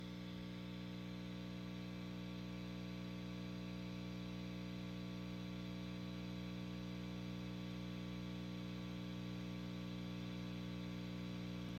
Very noisy Ultra-Lead
The hum happens as soon as the amp is turned on, but only amplified after being taken off standby, obviously.
Here is a clip of the clean channel. Master at 2 and channel volume at 0 with no loop or eq engaged. You can hear me flip the ground switch near the end.